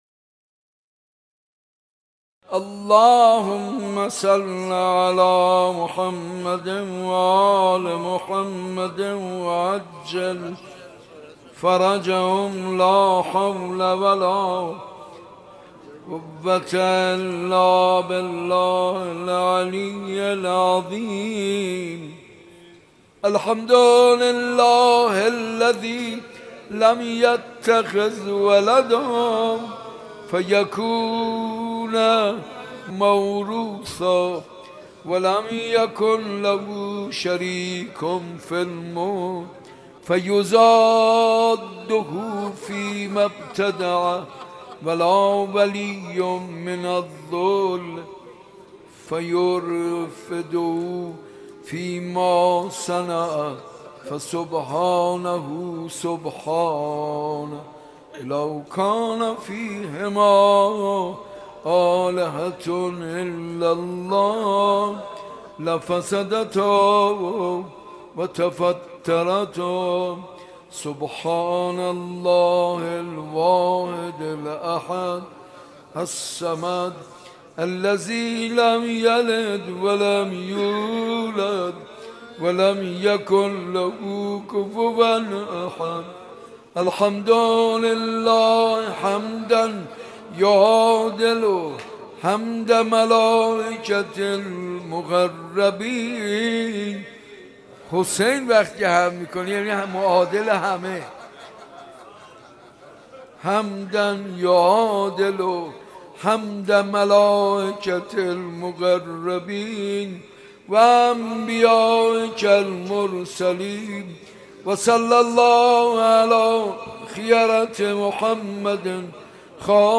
بخش دوم - بخشی از دعا و روضه